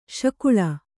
♪ śakuḷa